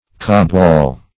Meaning of cobwall. cobwall synonyms, pronunciation, spelling and more from Free Dictionary.